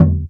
50 cent kick 4.wav